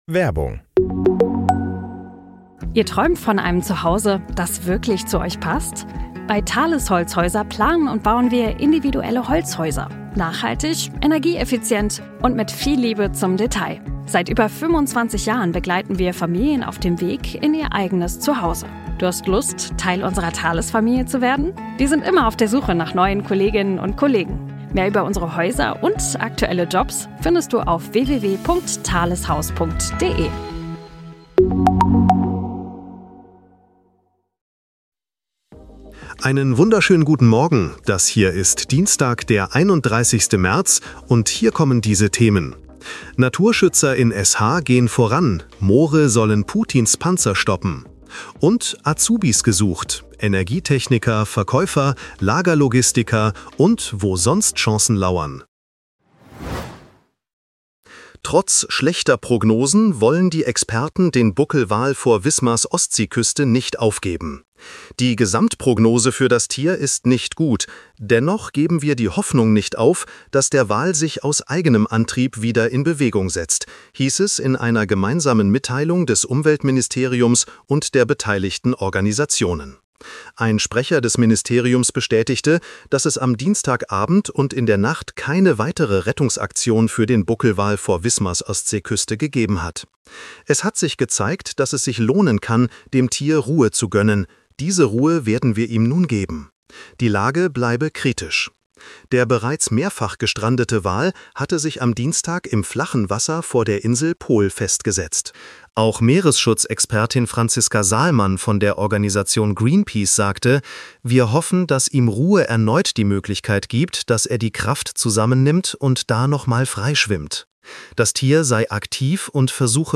Nachrichten-Botcast bekommst Du die wichtigsten Informationen aus